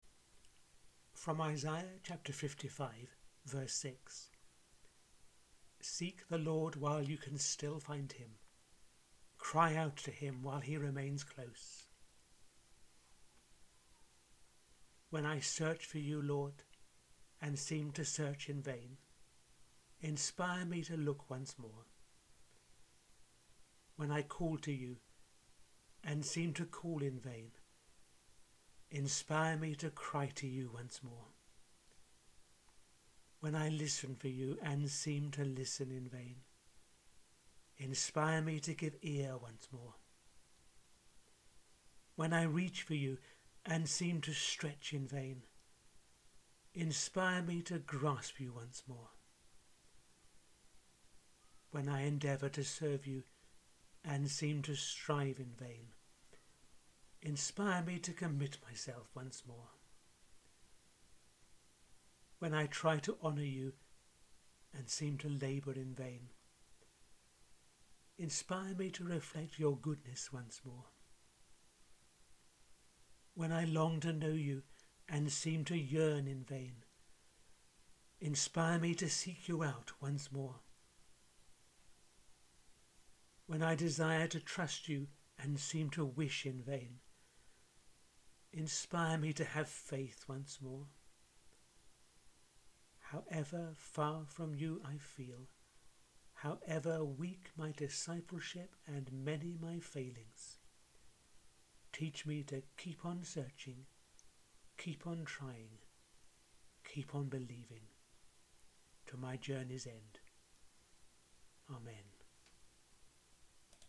The twelfth prayer in the audio series I’m running over these few weeks: